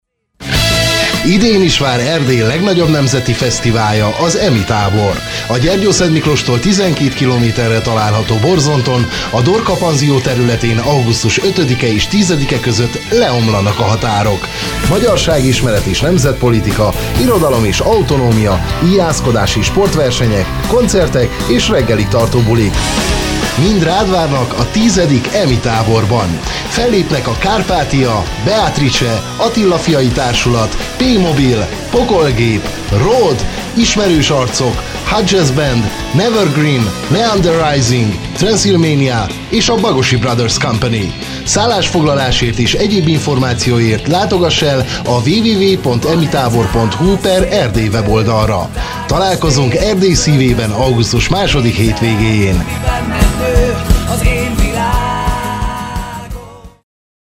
Emi_2014_radiospot.mp3